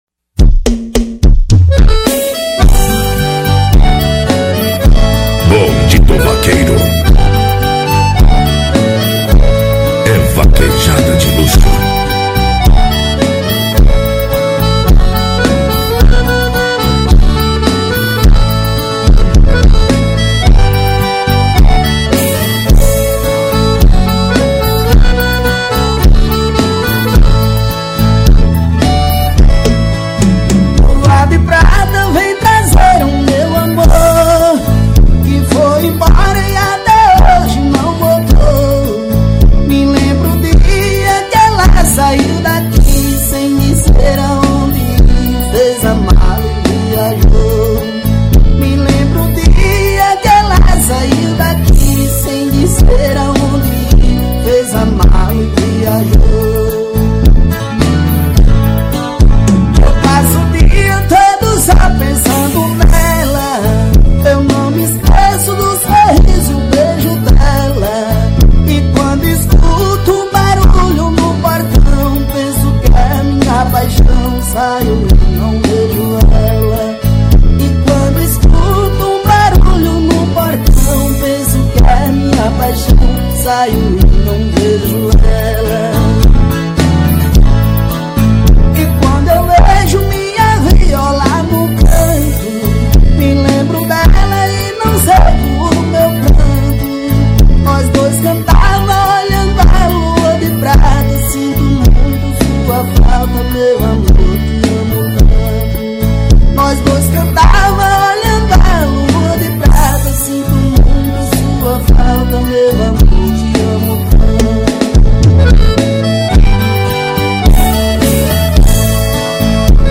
2024-02-21 06:47:51 Gênero: Forró Views